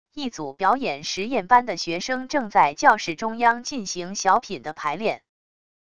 一组表演实验班的学生正在教室中央进行小品的排练wav音频